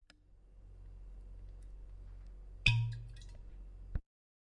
描述：在城市的一个车库里实地记录蜜蜂在某个酒厂的情况
标签： 葡萄酒 慕尼黑 现场记录 蜜蜂 城市
声道立体声